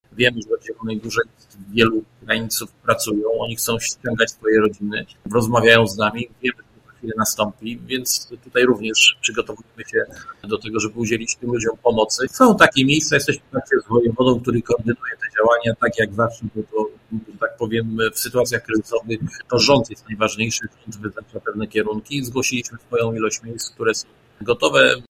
Prezydent Janusz Kubicki w porannej rozmowie na naszej antenie podkreślił, że od zakończenia II wojny światowej nie było takiej sytuacji w cywilizowanej Europie.